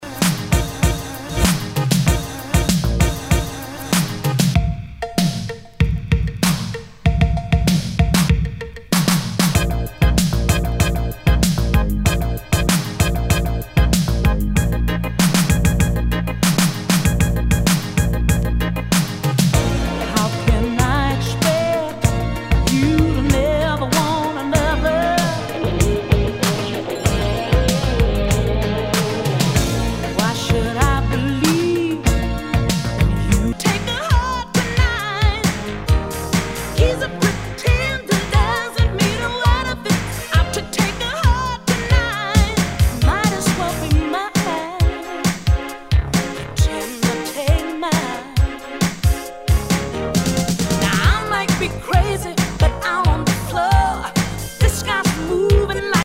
ROCK/POPS/INDIE
ナイス！シンセ・ポップ / ダウンテンポ・クラシック！